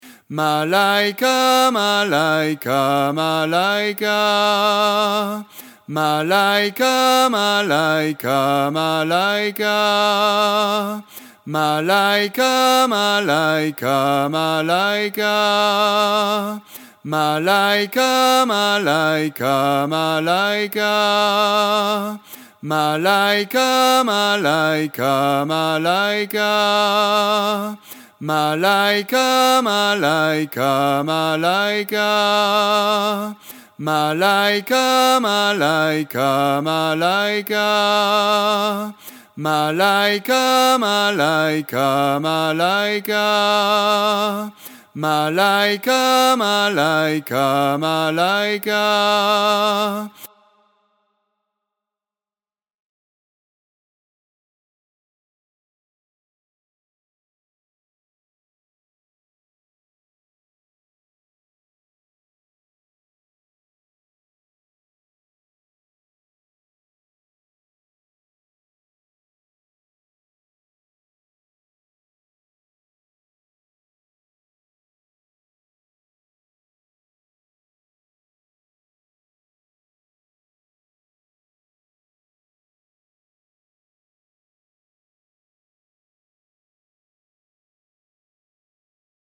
La voix 4 et son playback :